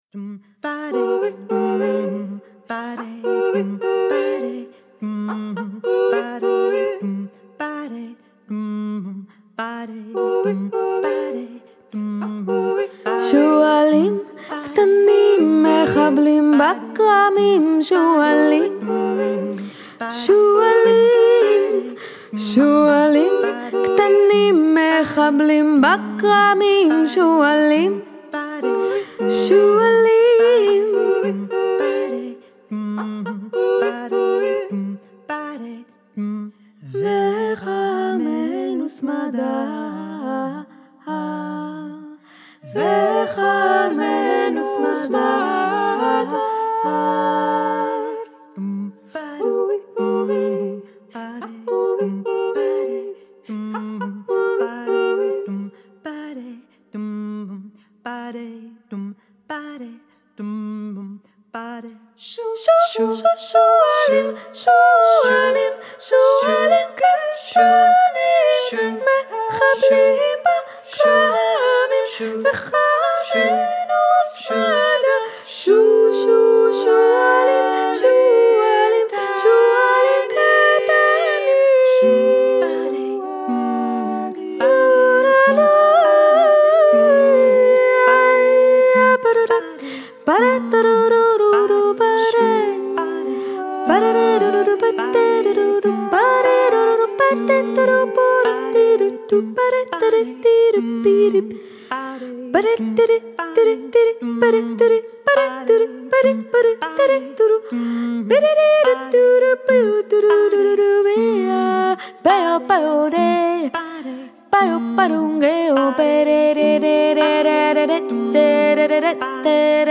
an evocative a cappella vocal quartet
they fashion lush harmonies and strong rhythmic drive
Genre: Jazz, Vocal, A Capella